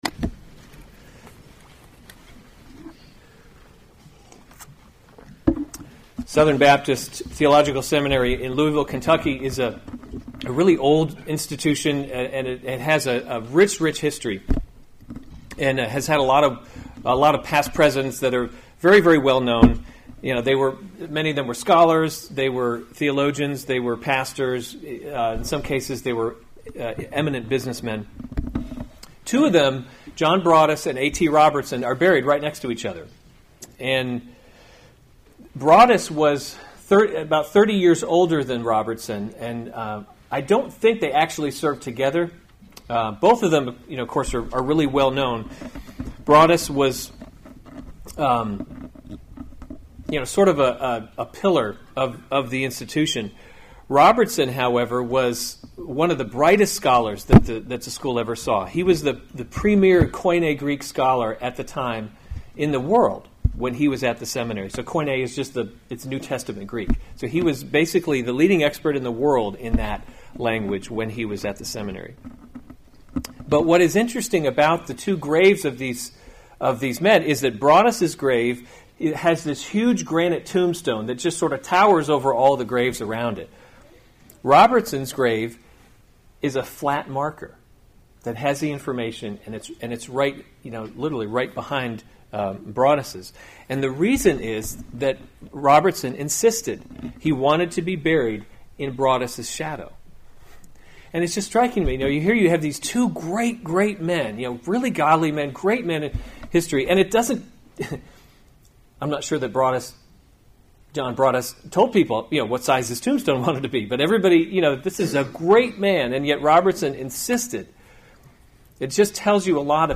May 20, 2017 1 Timothy – Leading by Example series Weekly Sunday Service Save/Download this sermon 1 Timothy 5:17 – 6:2 Other sermons from 1 Timothy 17 Let the elders who […]